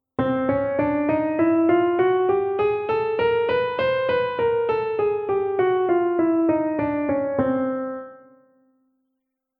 Wenn man alle Halbtöne spielt, nennt man dies eine chromatische Tonleiter.
TonleiternChromatischHalbton.mp3